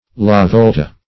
Search Result for " lavolta" : The Collaborative International Dictionary of English v.0.48: Lavolt \La*volt"\, Lavolta \La*vol"ta\, n. [It. la volta the turn, turning, whirl.